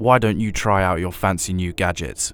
Voice Lines
Update Voice Overs for Amplification & Normalisation